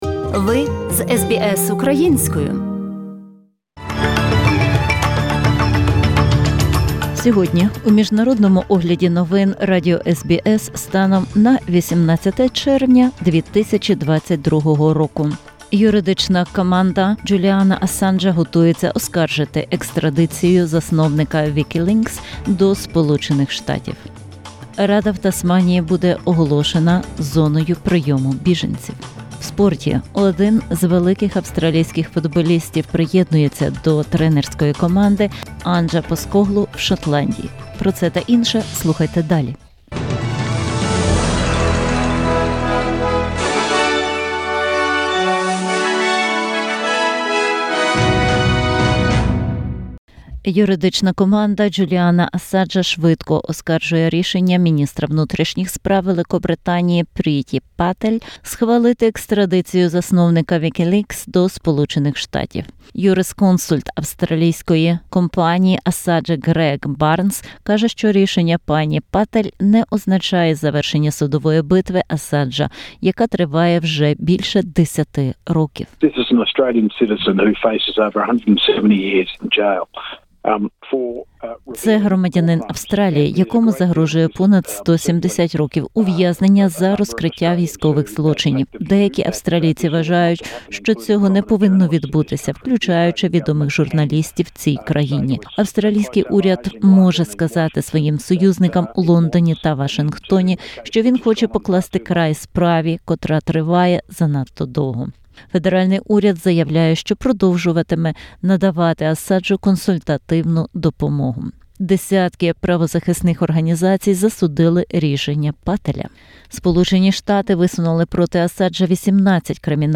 SBS News in Ukrainian - 18/06/2022